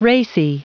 Prononciation du mot racy en anglais (fichier audio)
Prononciation du mot : racy